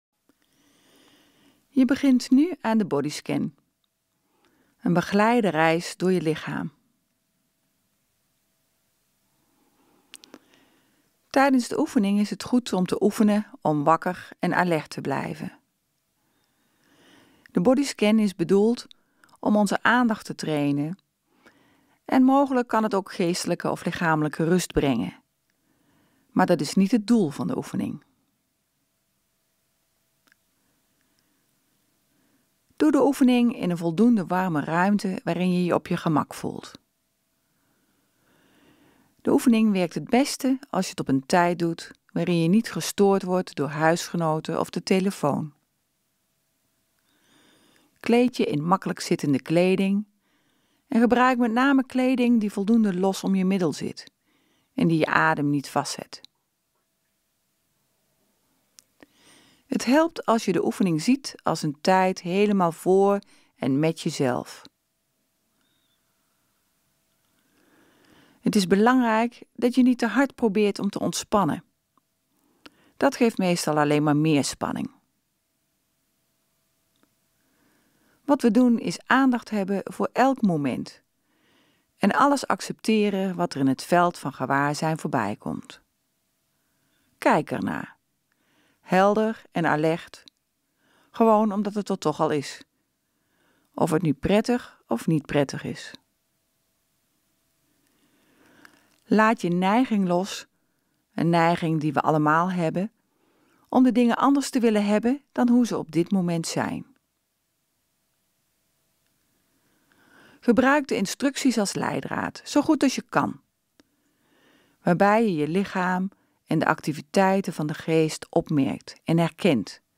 Bodyscan.mp3